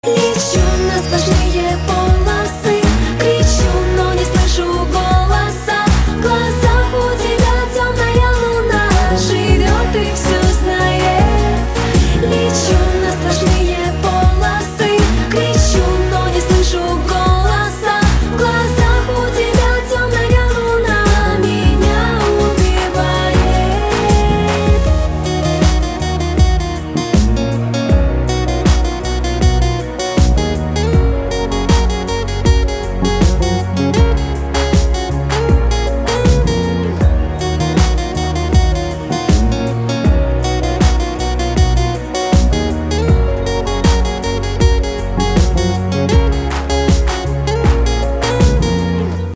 Танцевальные
Метки: поп, красивые, женский вокал, dance,